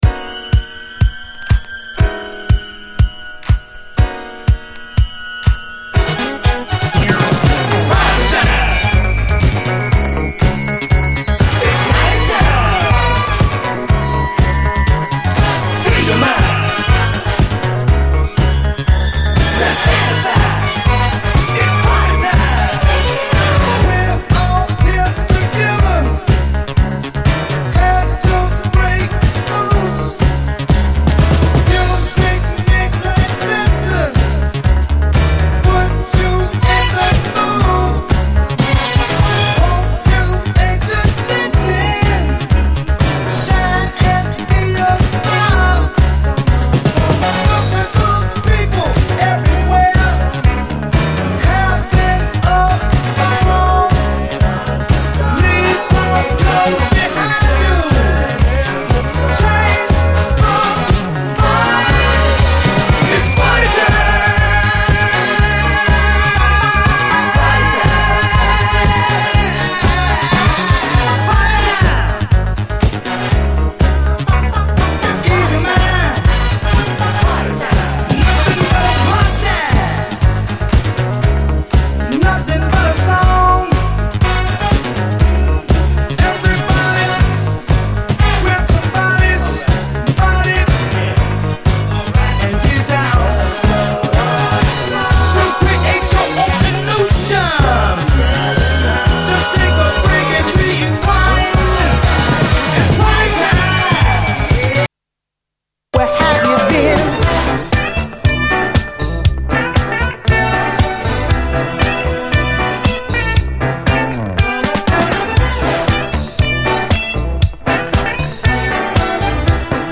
SOUL, 70's～ SOUL, DISCO
アーバンにフロアを彩る80’Sモダン・ブギー